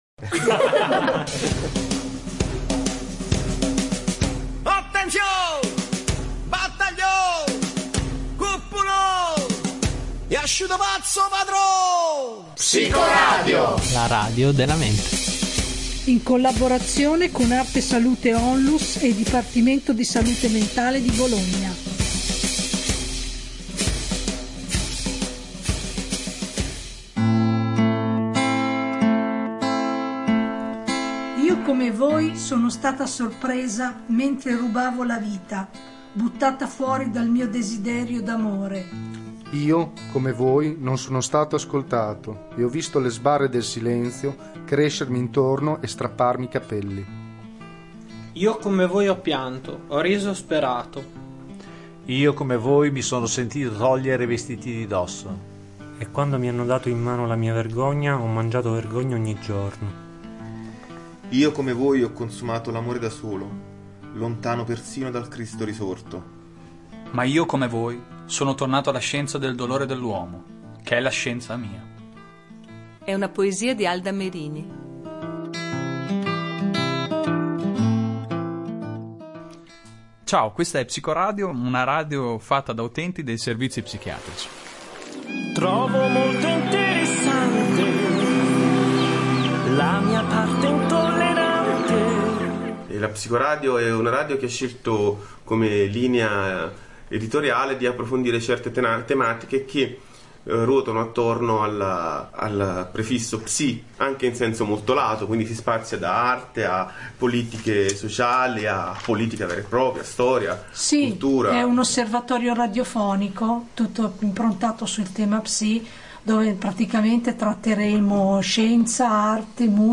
Psicoradio intervista Bergonzoni, Cristicchi ed Elio
Il vocabolario riporta tutte queste definizioni e noi siamo andati alla ricerca di altre risposte ancora, chiedendo alla gente per strada e ad alcuni personaggi famosi: Alessandro Bergonzoni, Simone Cristicchi ed Elio, di Elio e le Storie Tese. Ad Elio la parola pazzo ricorda la pizza, per Bergonzoni di normale per fortuna non c’è niente.